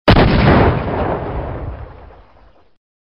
Все звуки натуральные.
Звук взрыва
Звук мощный и раскатистый